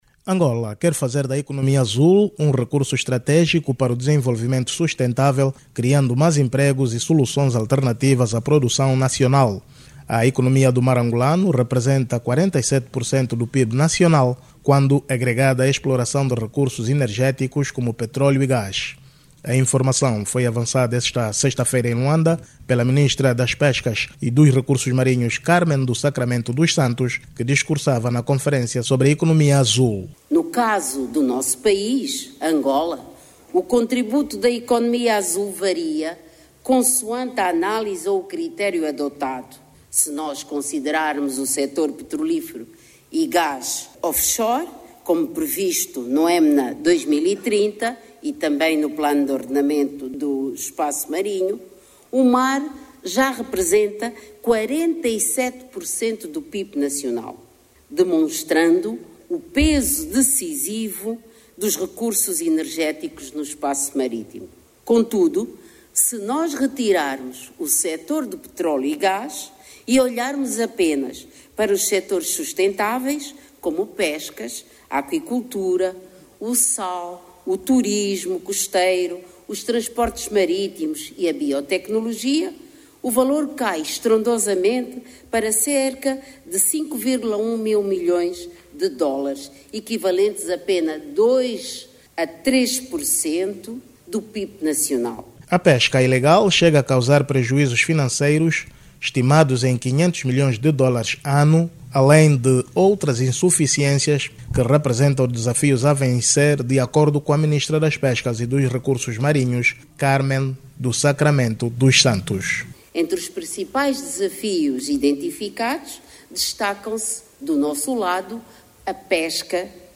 Cármen do Sacramento Neto falava na abertura da 5.ª Conferência sobre Economia Azul, Financiamento e Valorização do Cluster do Mar, realizada esta manhã em Luanda.